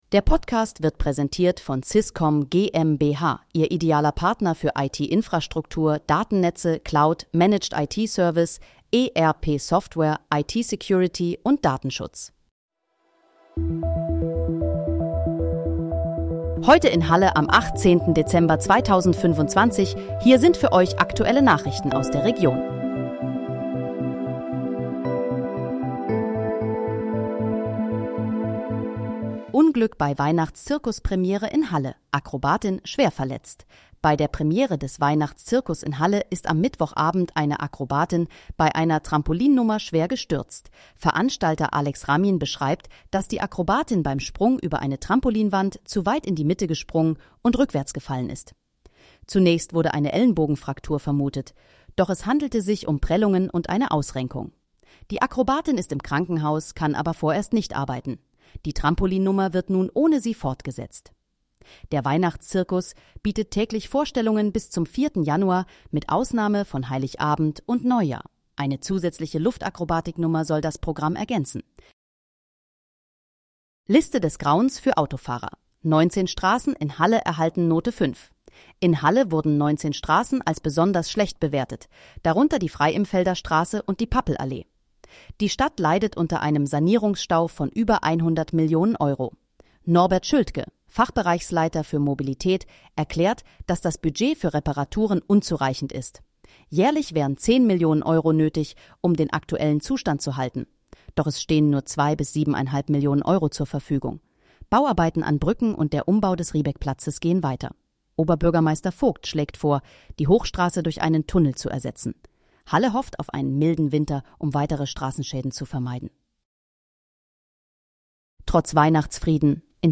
Heute in, Halle: Aktuelle Nachrichten vom 18.12.2025, erstellt mit KI-Unterstützung
Nachrichten